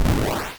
simple-hit.wav